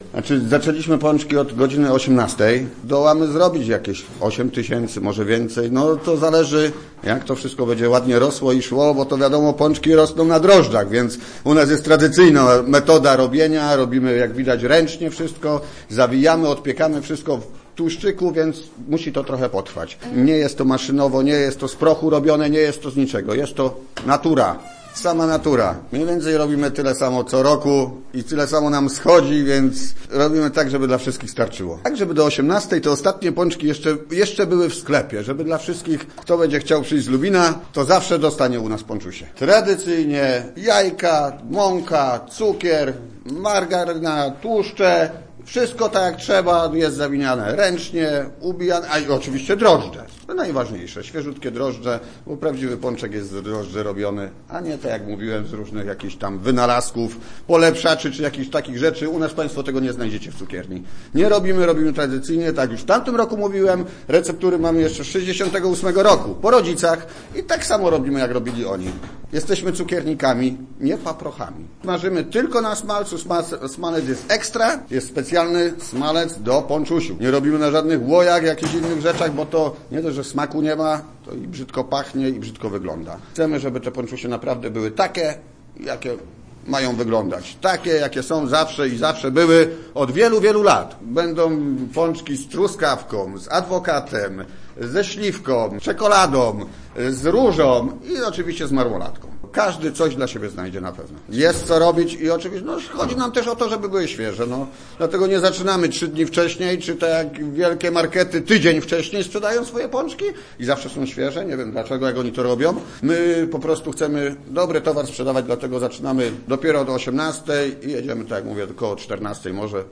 Z naszym mikrofonem odwiedziliśmy jedną z lubińskich piekarni w centrum miasta.